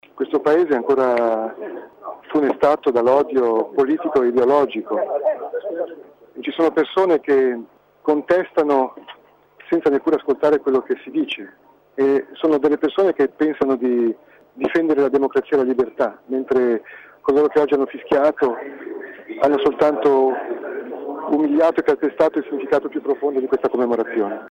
bondi_dichiarazione.mp3